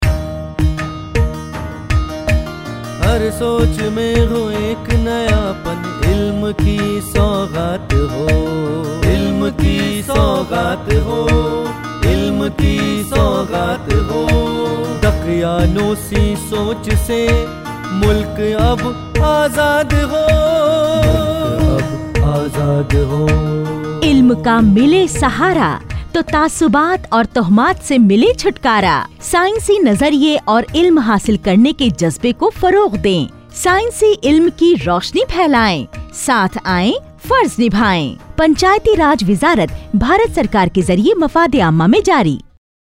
241 Fundamental Duty 8th Fundamental Duty Develop scientific temper Radio Jingle Urdu